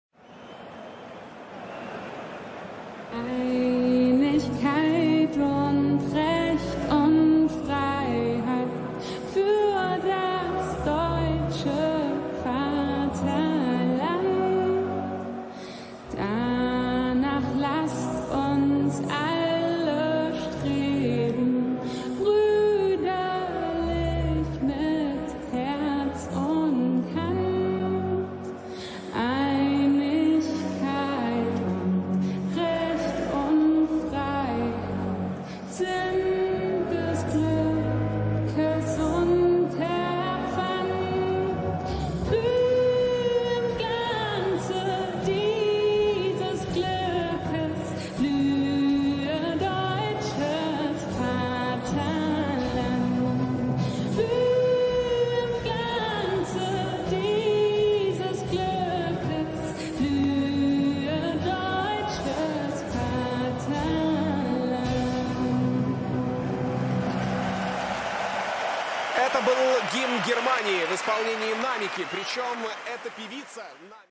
German National Athem by Namika, Allianz Arena Stadium, Munich.